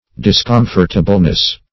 -- Dis*com"fort*a*ble*ness, n. [Obs.]